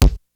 RX5 KICK.wav